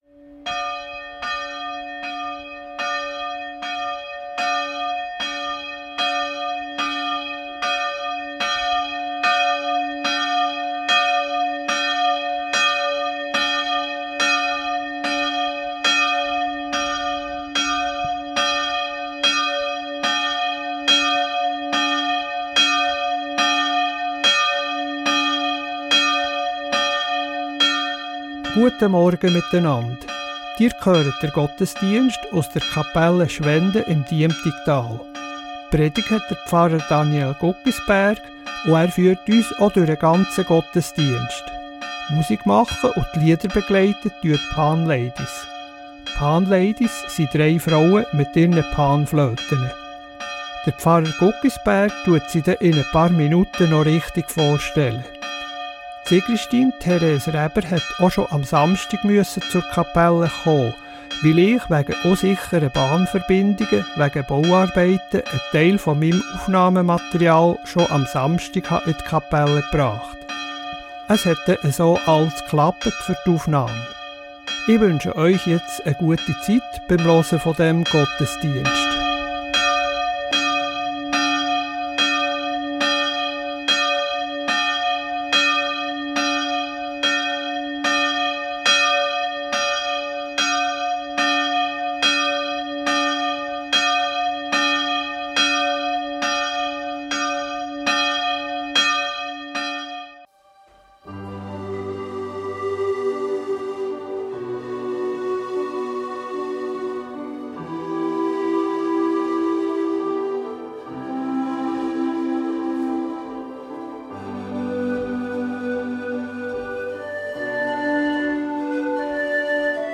Kapelle Schwenden der reformierten Kirchgemeinde Diemtigen ~ Gottesdienst auf Radio BeO Podcast
BeO Gottesdienst